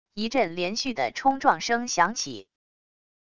一阵连续的冲撞声响起wav音频